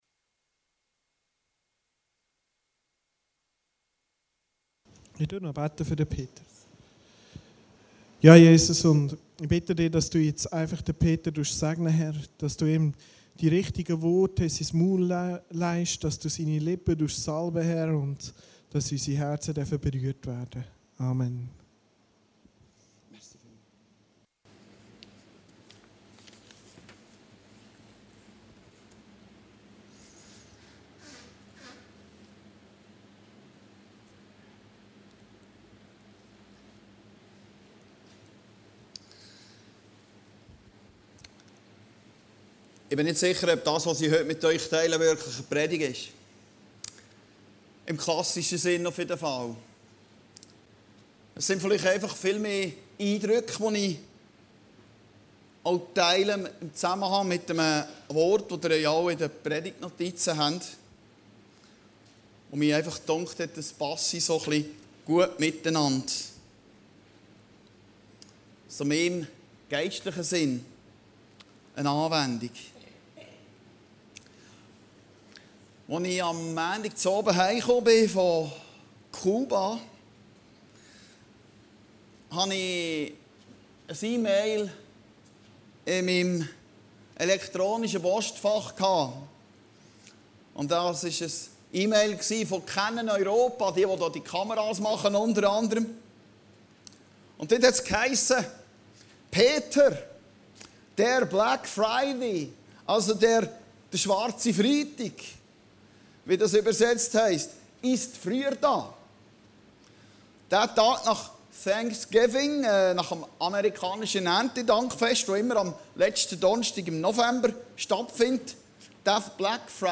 Predigten Heilsarmee Aargau Süd – In jeder Lebenslage zufrieden sein